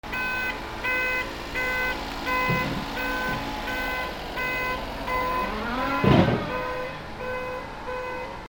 車 警告音
『プー プー』